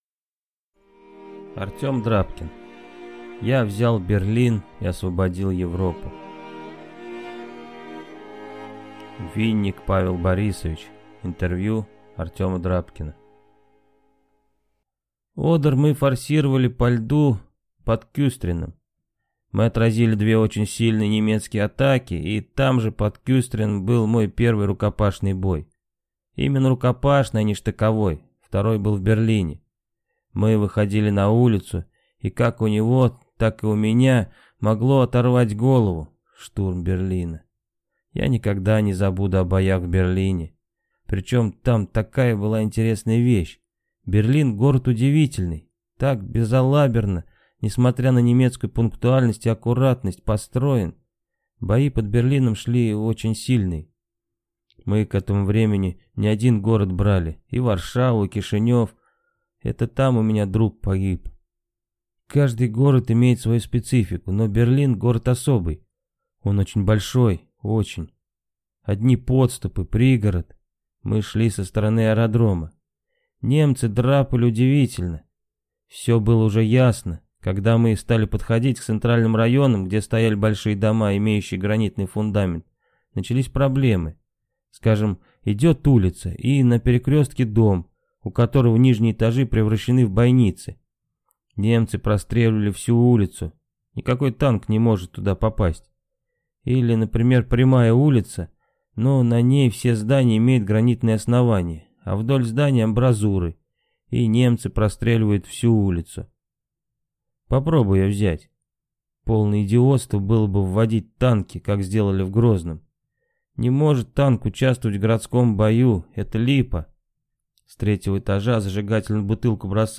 Аудиокнига Я взял Берлин и освободил Европу | Библиотека аудиокниг